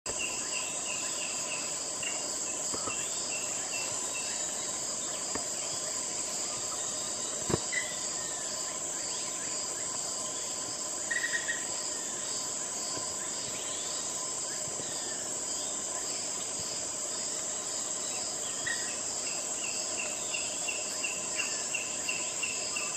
Titirijí Flamulado (Hemitriccus flammulatus)
Nombre en inglés: Flammulated Bamboo Tyrant
Fase de la vida: Adulto
Localidad o área protegida: Est. biológica Villa Carmen
Condición: Silvestre
Certeza: Vocalización Grabada